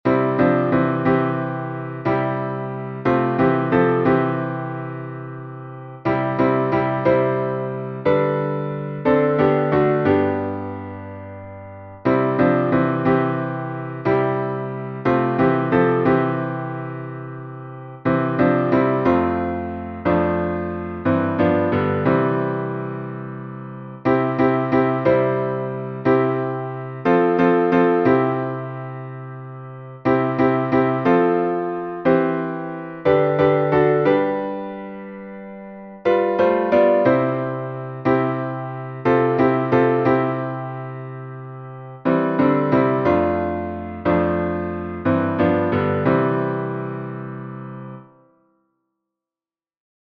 salmo_47B_instrumental.mp3